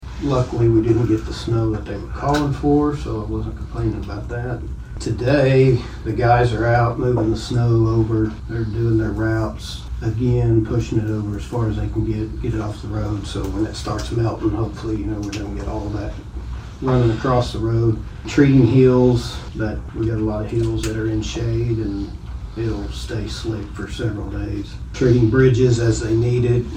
District 2 Commissioner Corey Shivel gave an
update during Monday morning's meeting.
Corey Shivel on County Roads 1-26.mp3